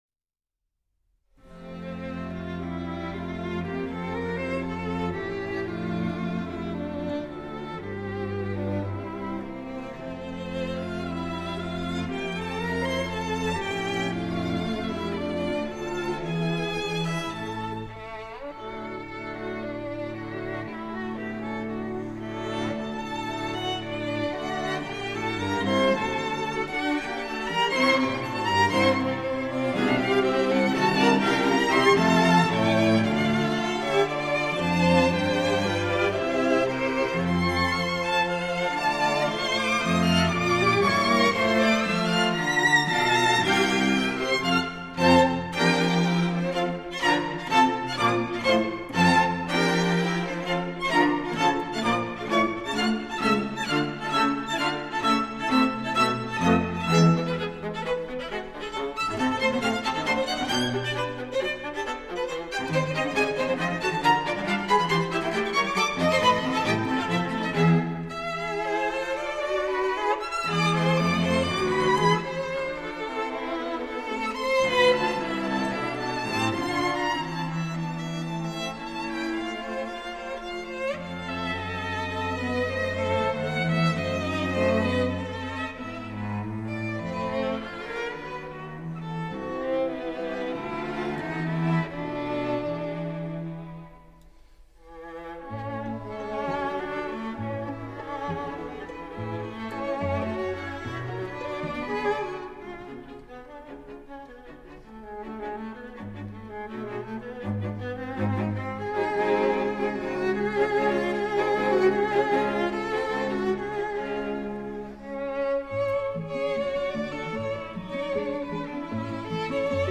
Quintet